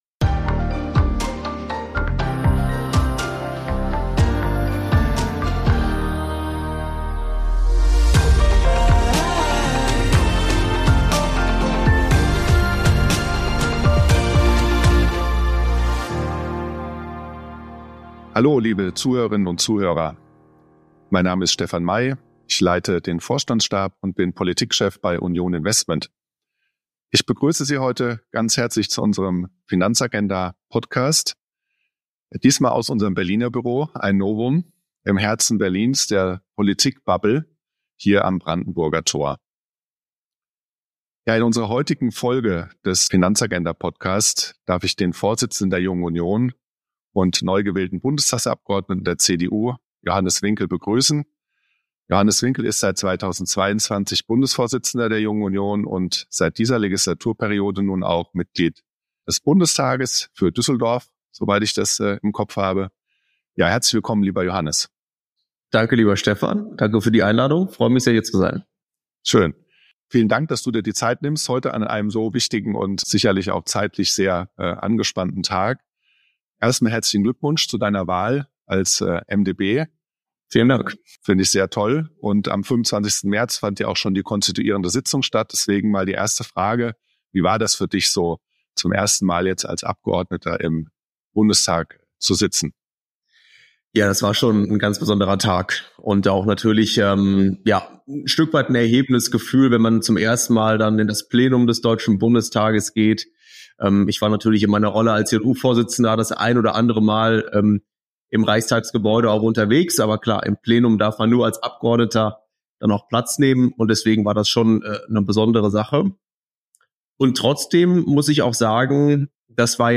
#4 - Im Austausch mit Johannes Winkel zu Sondervermögen und Rentenpolitik ~ FinanzAgenda Podcast